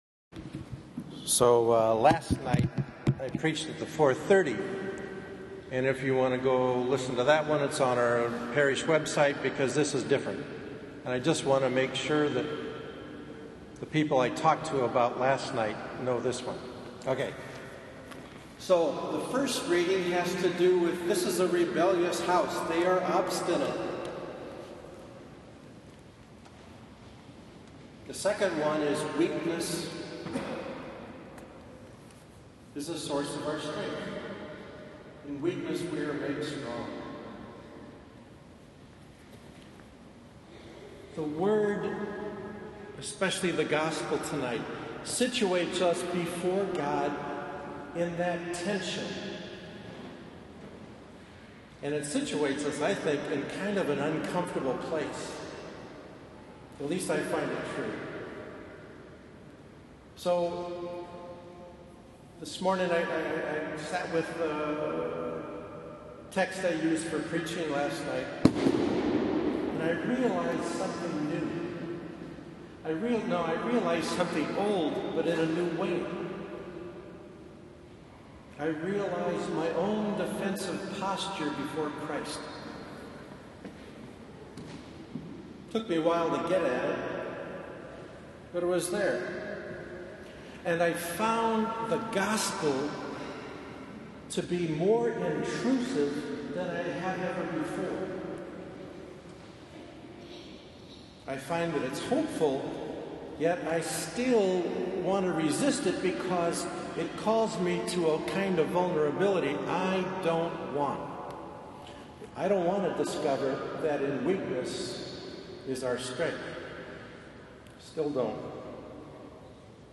Yes, this is actually a different homily on the following day (Sunday) on the same readings.